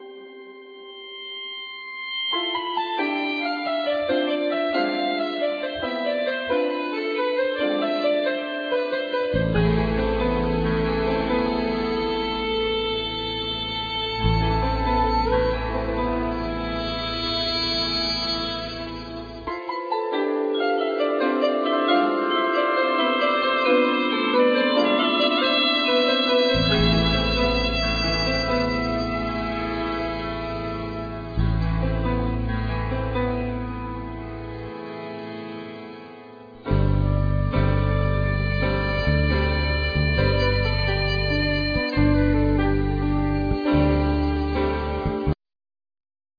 Flute,Piano,Percussion
Violin
Acoustic guitar,Dombra,Bayan